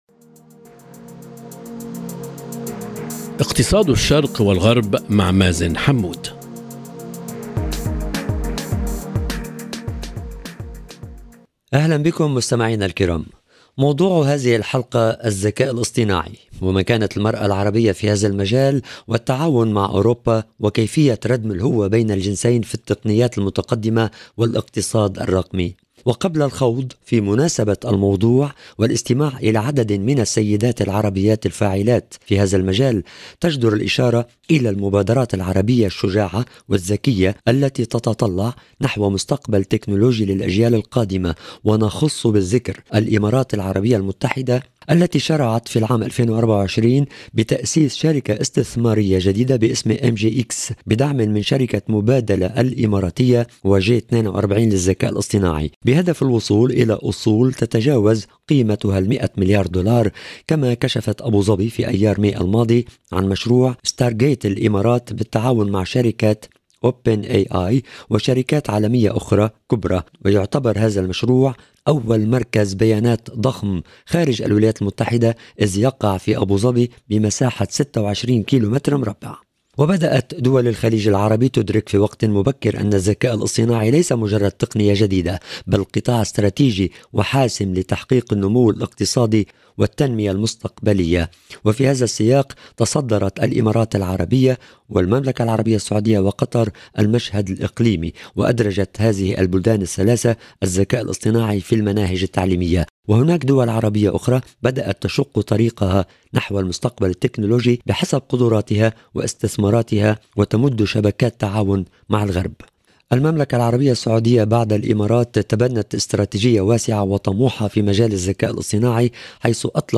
حلقة تجمع ثلاث قيادات بارزات في مجالات الاقتصاد الرقمي والتحول التكنولوجي: